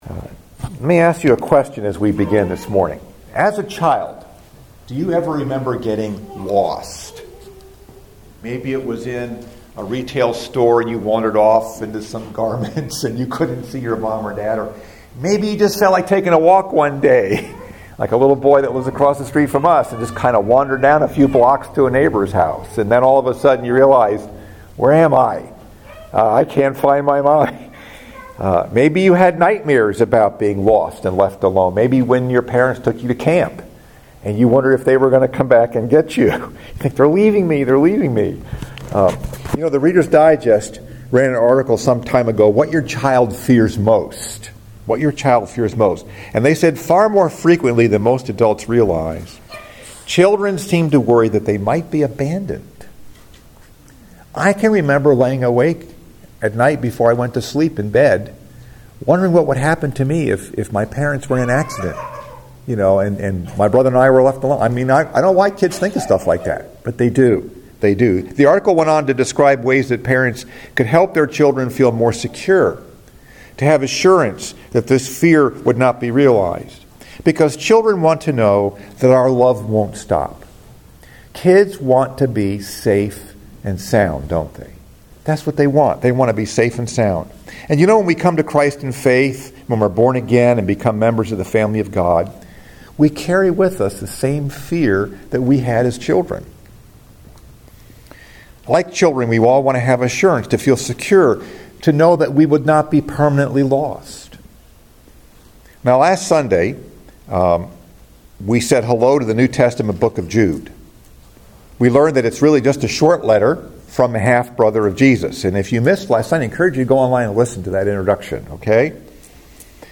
Message: “Safe & Sound” Scripture: Jude 1, 2